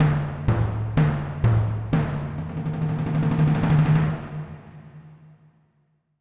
Drum Roll (2).wav